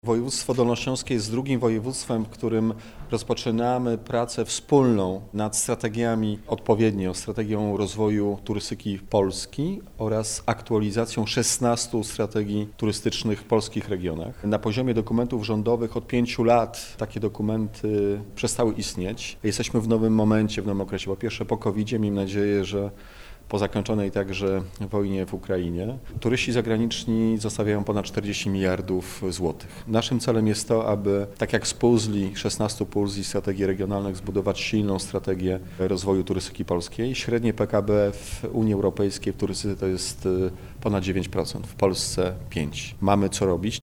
– Polska turystyka to bardzo ważna gałąź polskiej gospodarki, to blisko 5% PKB, 100 mld zł przychodu i blisko 40 mln turystów polskich i zagranicznych– mówił we Wrocławiu Piotr Borys, wiceminister Sportu i Turystyki